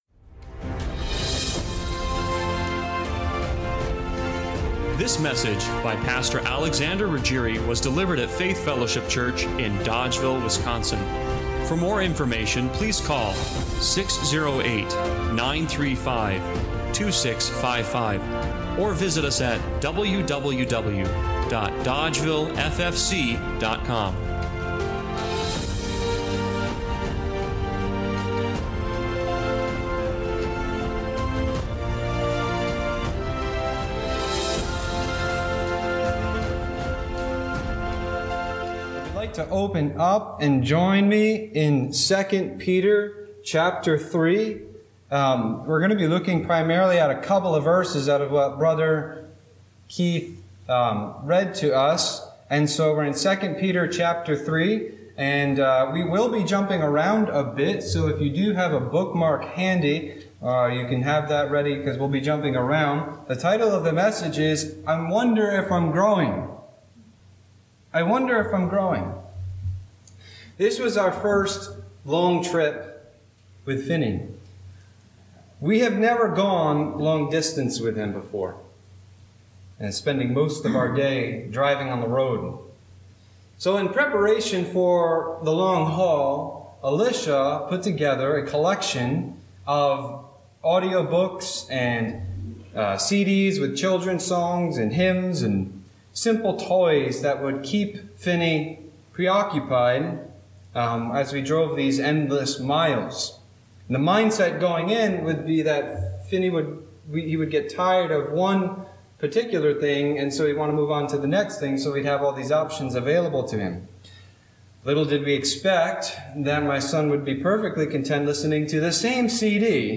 2 Peter 3:14-18 Service Type: Sunday Morning Worship Bible Text